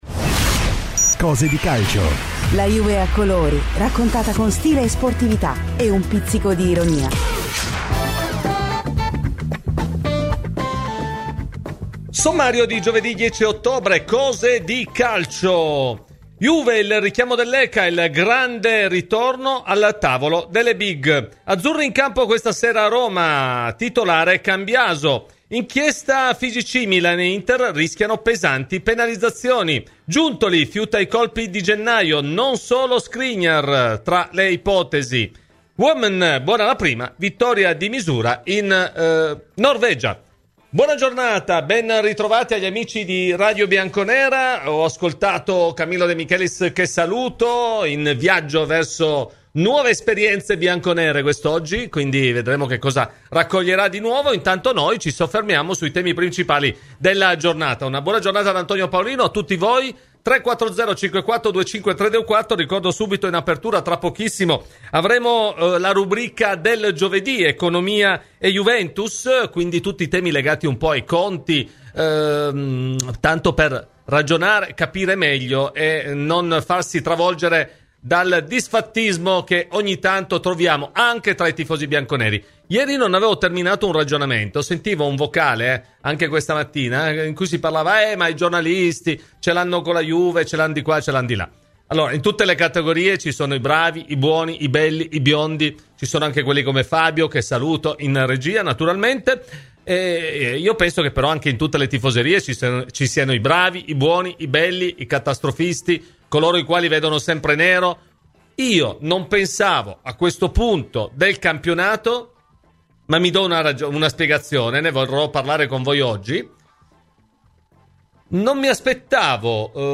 a RBN